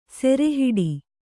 ♪ sere hiḍu